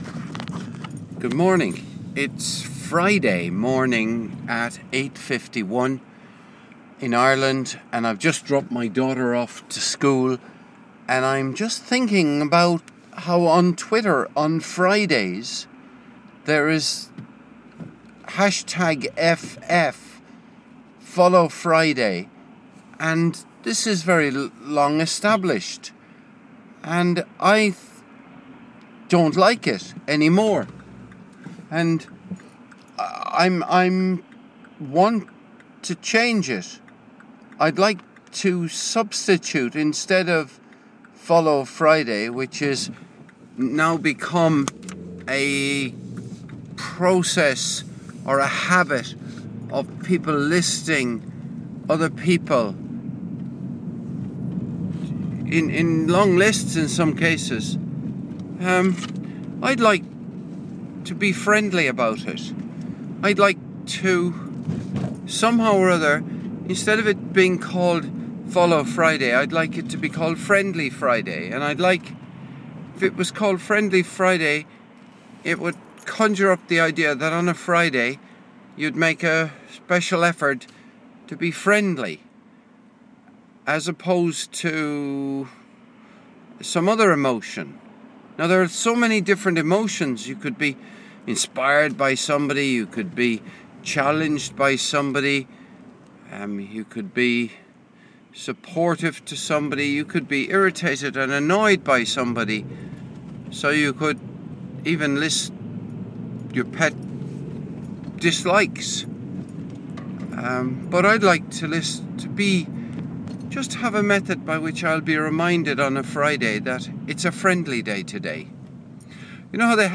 in the car after morning Schoolrun